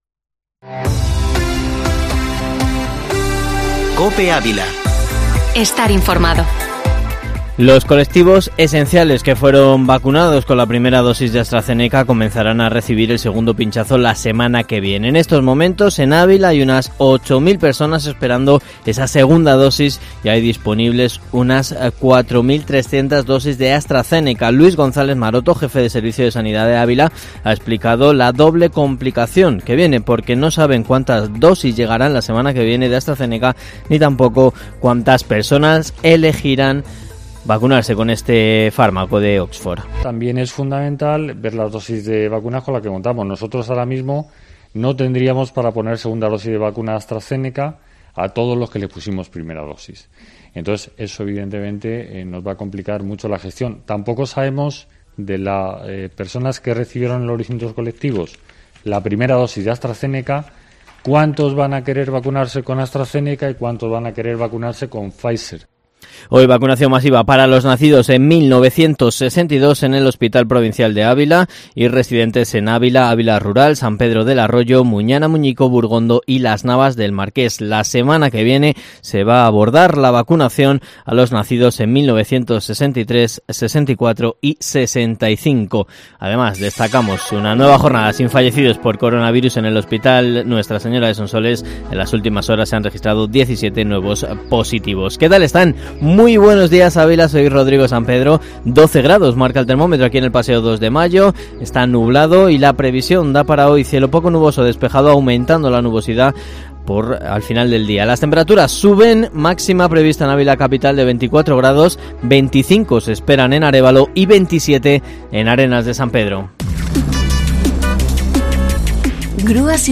Informativo matinal Herrera en COPE Ávila 26/05/2021